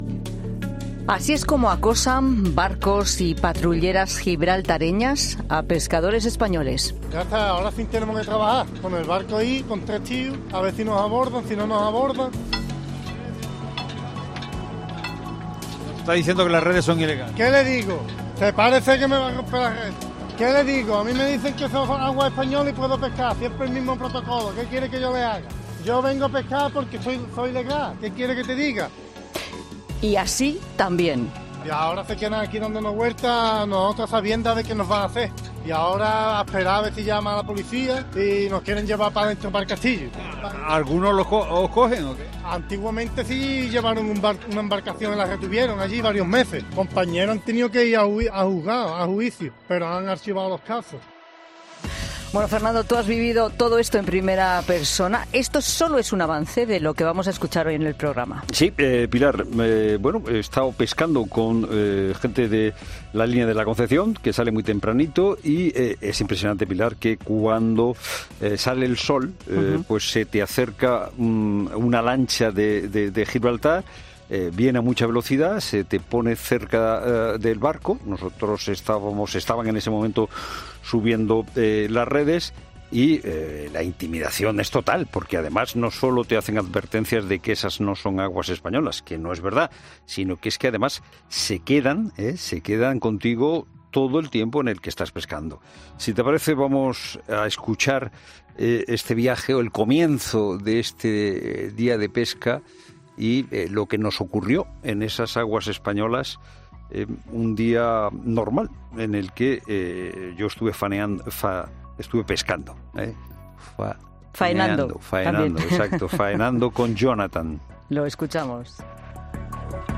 Así arranca este reportaje.